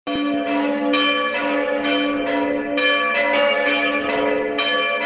Öffnungszeiten: Im Winterhalbjahr montags, dienstags, donnerstags und freitags von 10.00 Uhr bis 17.00 Uhr MP3 | 79 KB Geläut Rodheim
Gelaeut_Rodheim.mp3